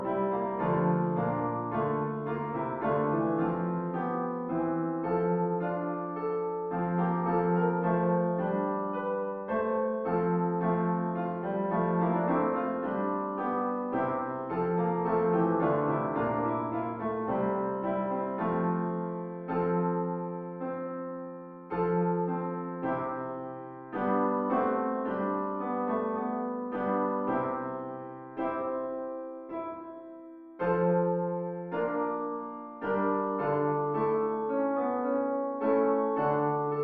- Dixit Maria (Men)